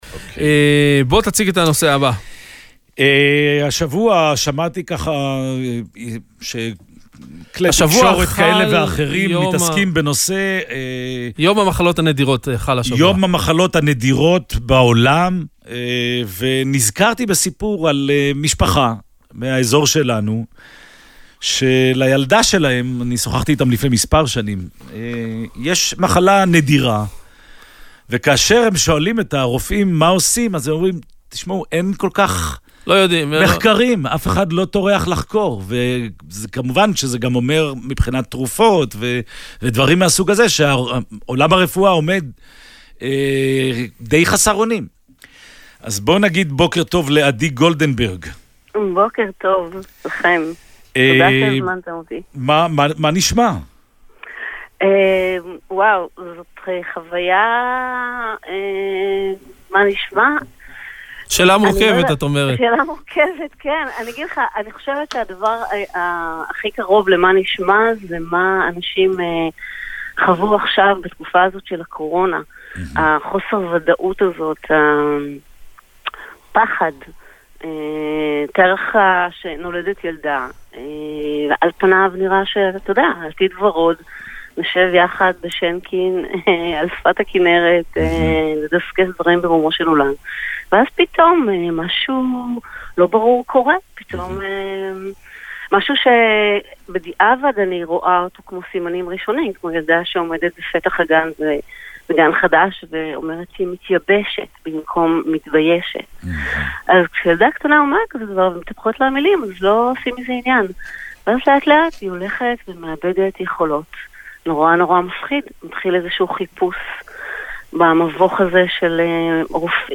ראיון ברדיו 96FM • עמותת עלמי